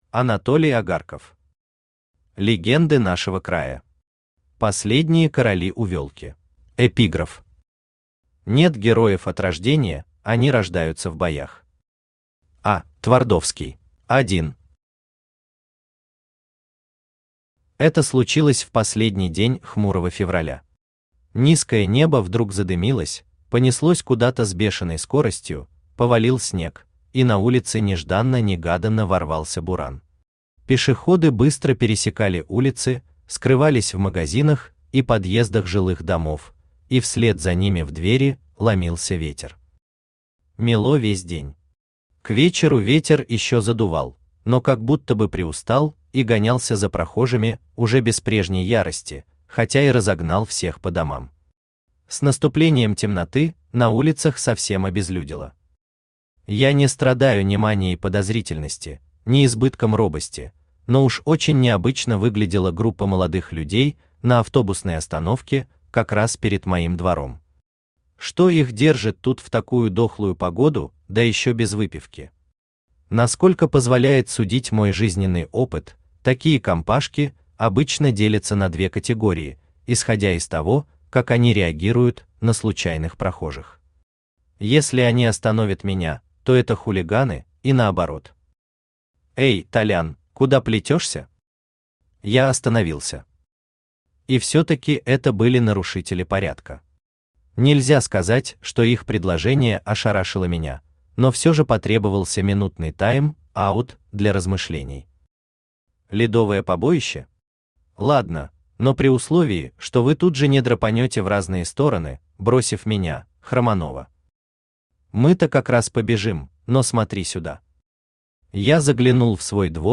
Аудиокнига Легенды нашего края. Последние короли Увелки | Библиотека аудиокниг
Последние короли Увелки Автор Анатолий Агарков Читает аудиокнигу Авточтец ЛитРес.